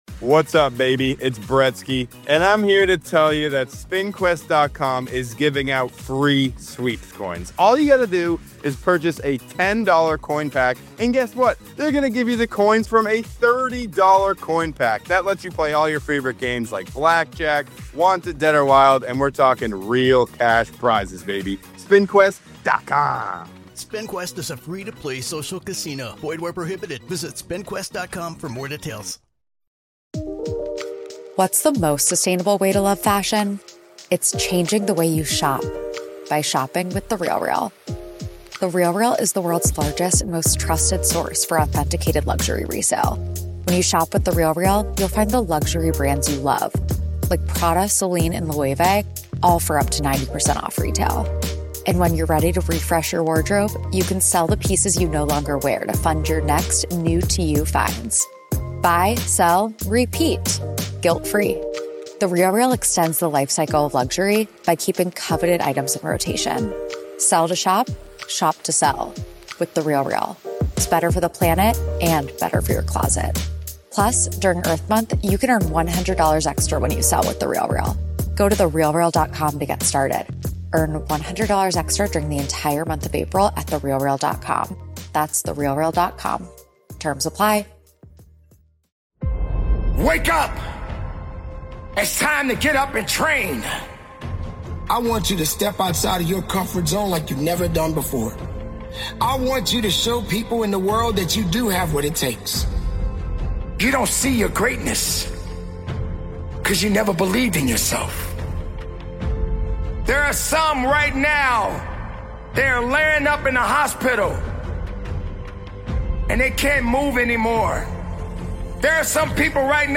Powerful Motivational Speech